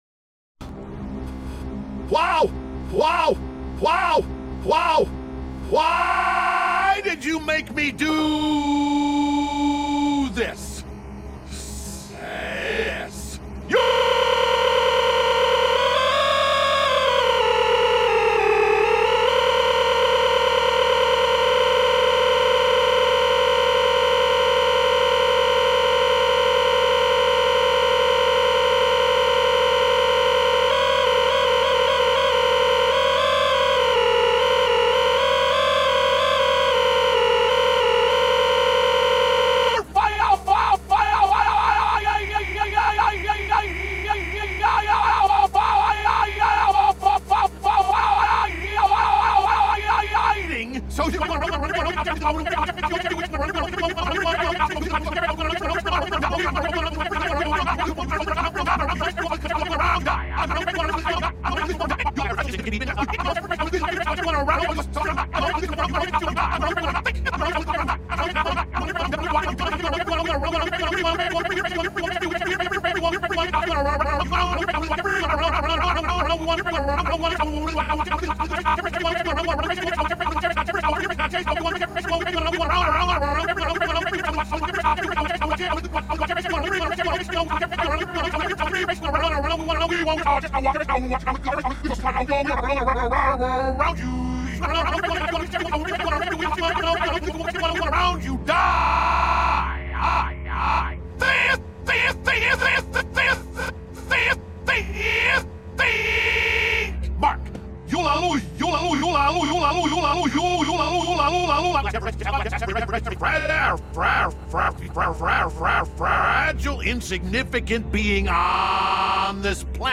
audio stretched up think mark
You Just Search Sound Effects And Download. tiktok sound effects meme Download Sound Effect Home